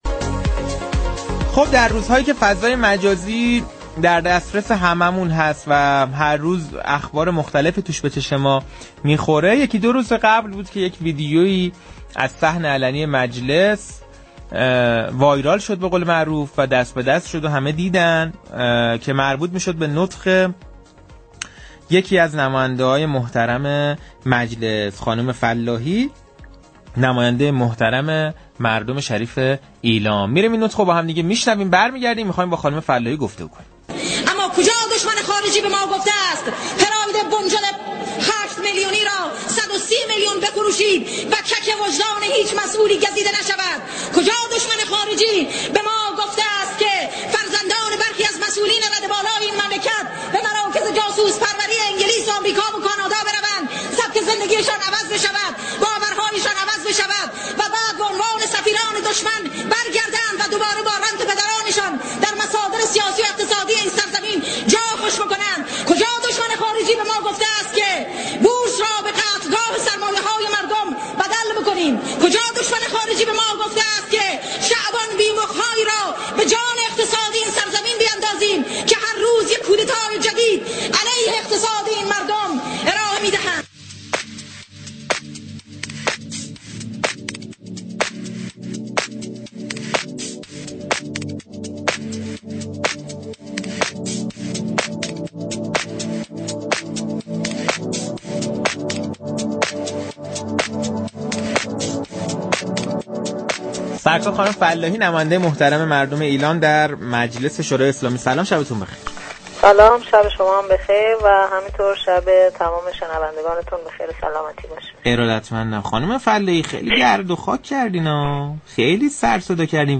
سارا فلاحی نماینده مردم ایلام در مجلس یازدهم در گفتگو